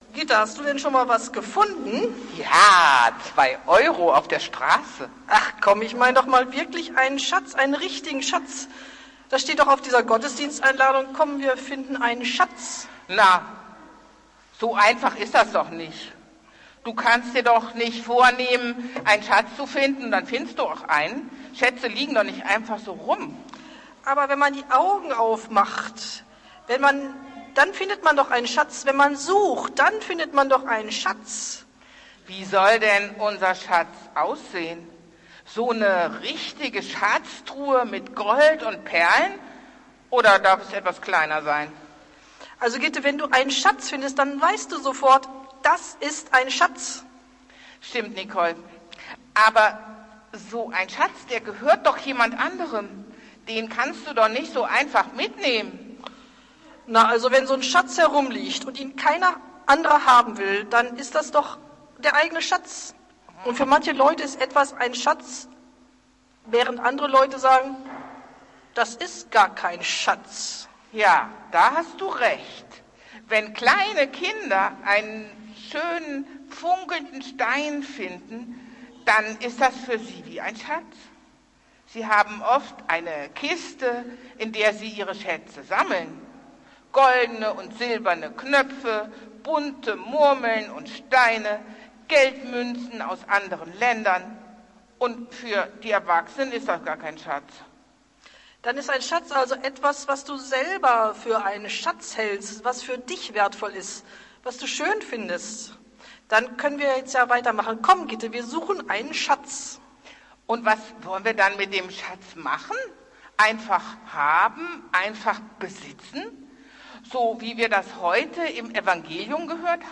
Predigt des Gottesdienstes unter dem Motto, „Gottesdienst einfach zusammen feiern!“, aus der Eckardtskirche vom 2. Juli 2023
Es handelte sich um einen Gottesdienst in einfacher Sprache.